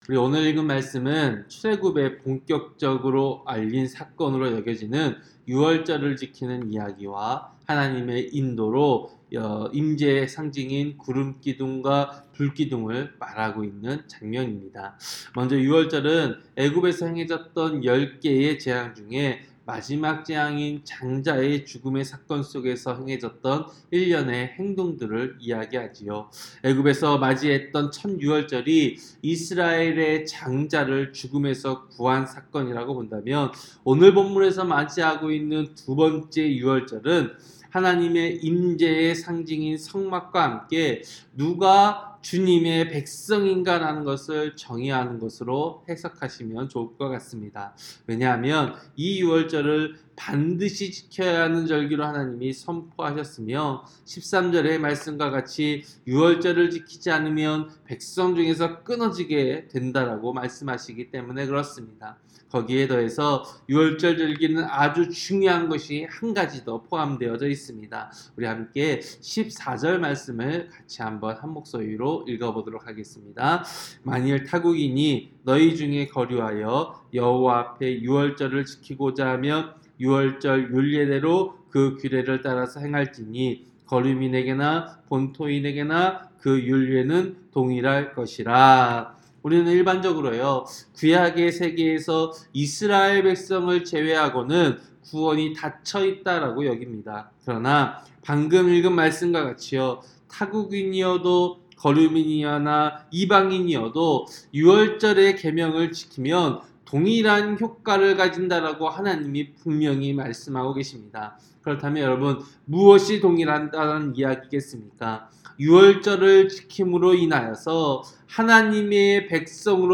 새벽설교-민수기 9장